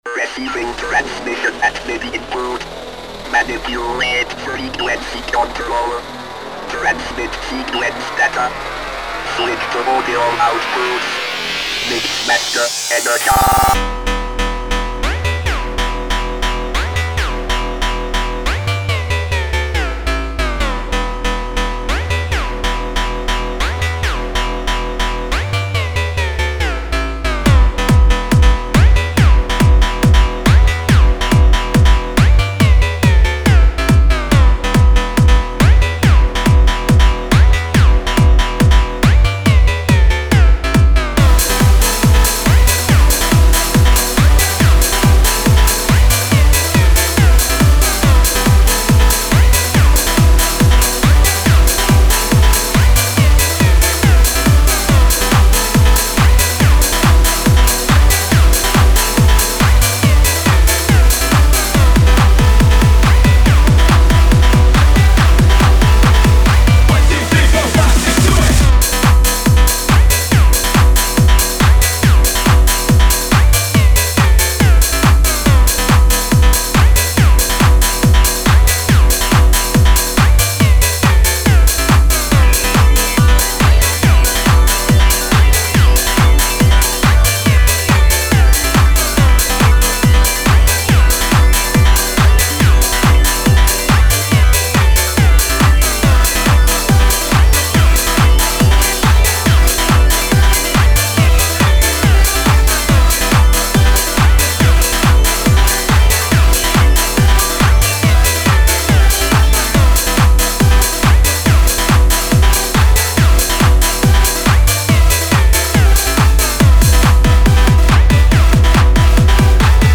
posted 2 years ago I came up with this on my own, starting out as a jam session, but evolved into something more!
Music / Trance
techno trance music electro